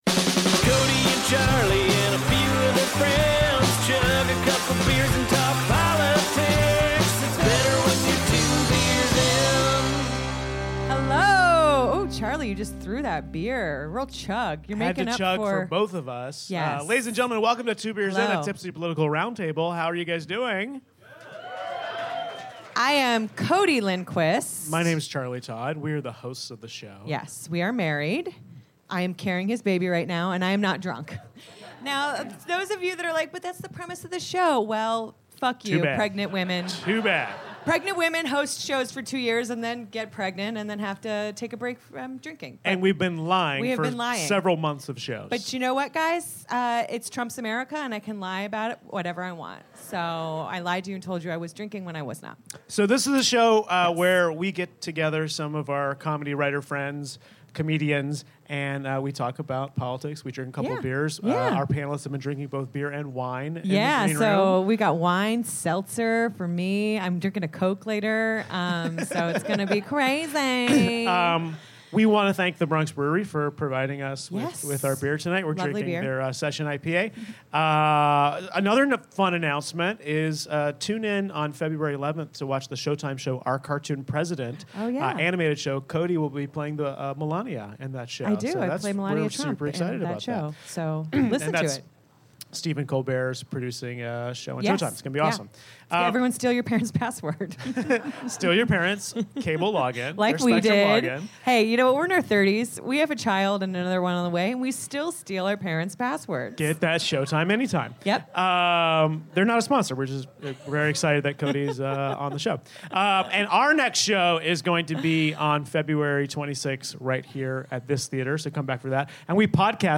join us for a tipsy round table at UCB Theatre East Village.